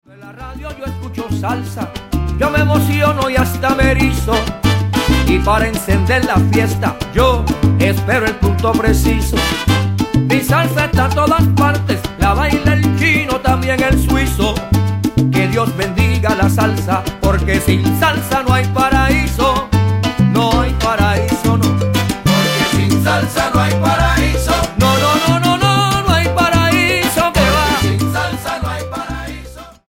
Salsa Charts - Februar 2011